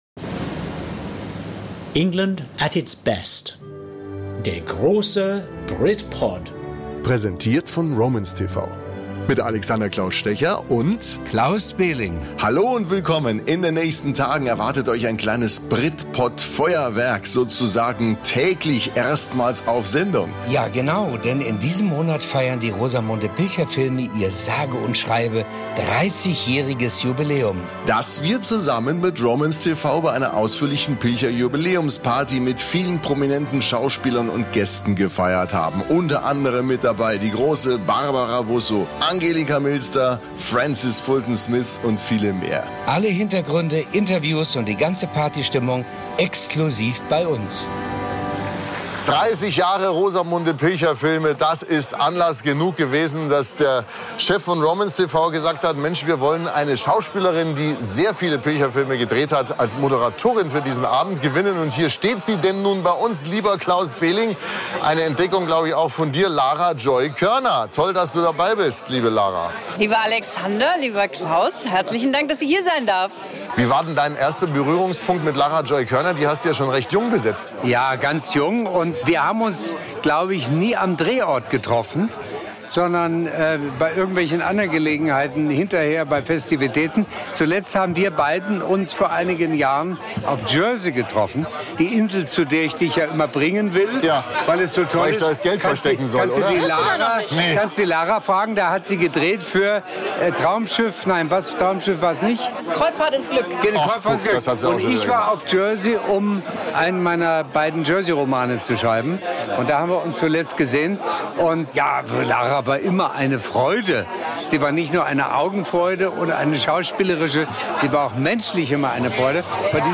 gesehen! BRITPOD Spezial berichtet exklusiv von den Feierlichkeiten